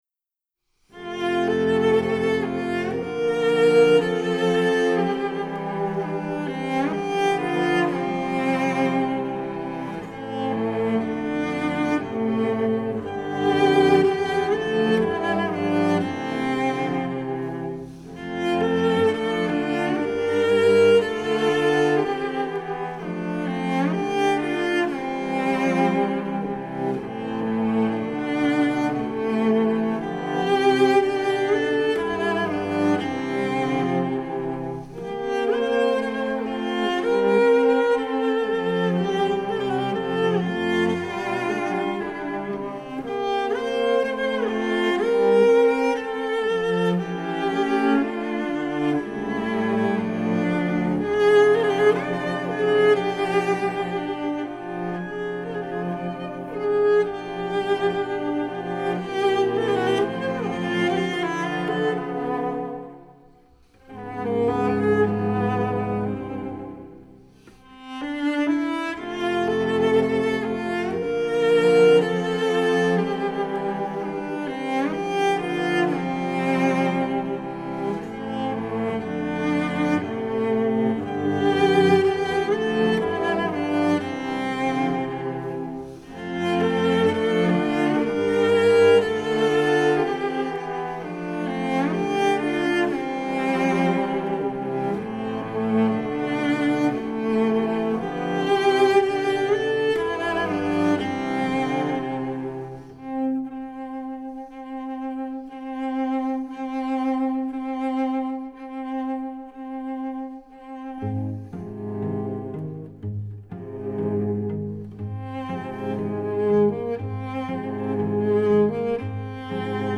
Voicing: 4 Cello